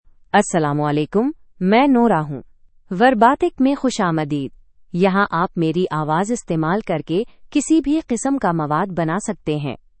Nora — Female Urdu (India) AI Voice | TTS, Voice Cloning & Video | Verbatik AI
Nora is a female AI voice for Urdu (India).
Voice sample
Female
Nora delivers clear pronunciation with authentic India Urdu intonation, making your content sound professionally produced.